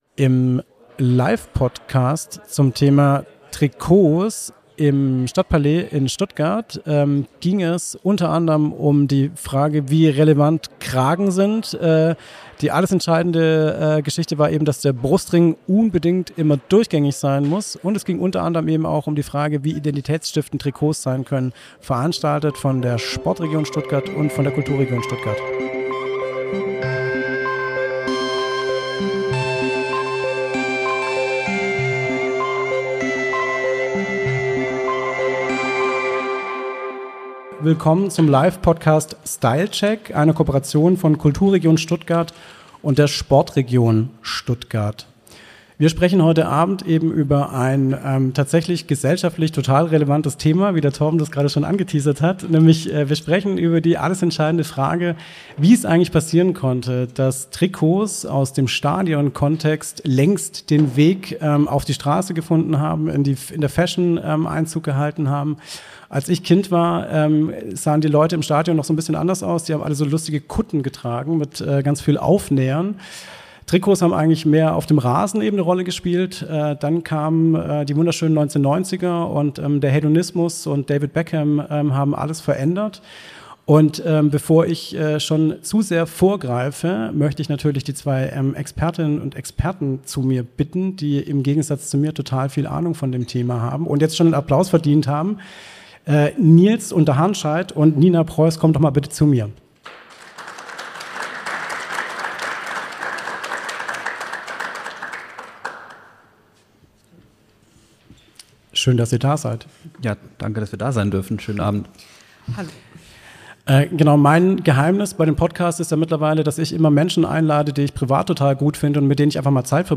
Der gemeinsame Live-Podcast von SportRegion und KulturRegion